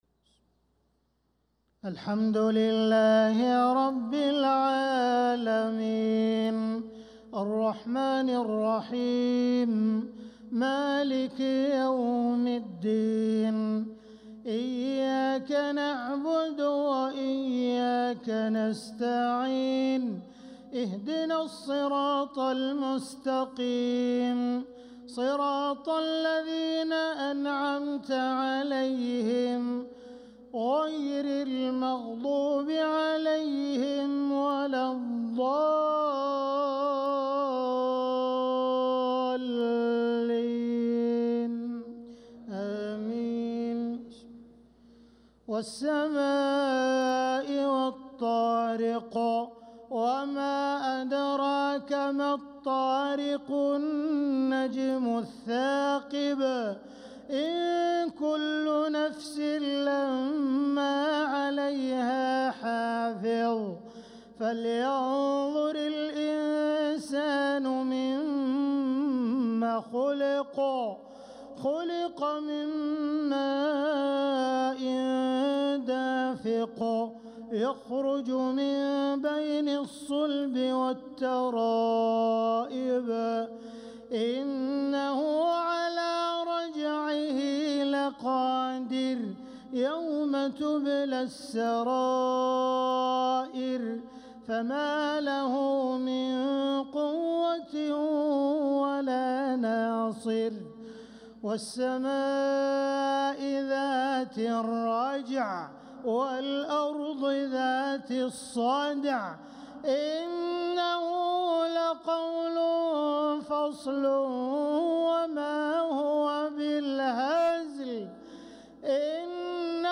صلاة العشاء للقارئ عبدالرحمن السديس 15 صفر 1446 هـ
تِلَاوَات الْحَرَمَيْن .